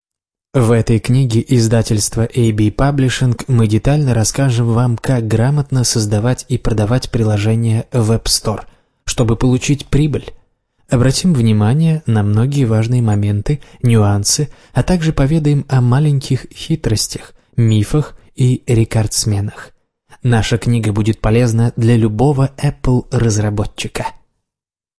Аудиокнига Как заработать в AppStore | Библиотека аудиокниг
Прослушать и бесплатно скачать фрагмент аудиокниги